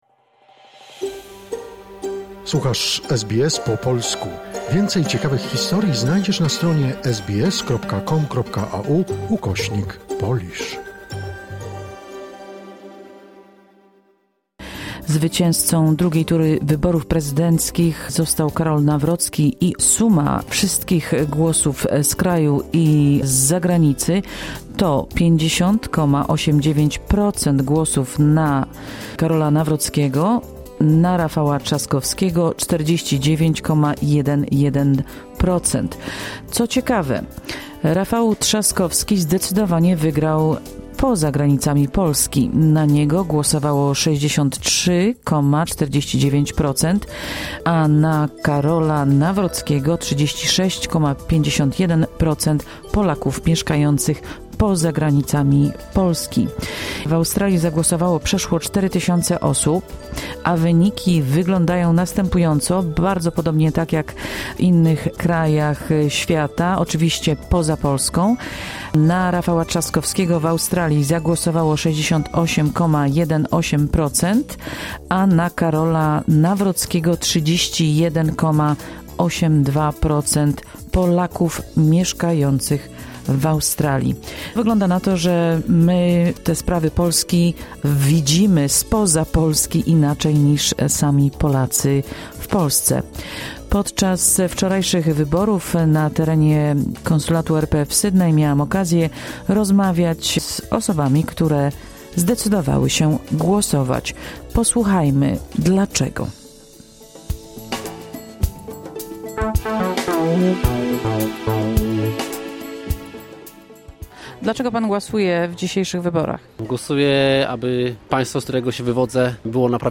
Tak mówili Polacy, którzy wzięli udział w II turze wyborów prezydenckich w Australii – dla wielu z nich pomimo odległości od ojczyzny, sprawy polskie są im bliskie..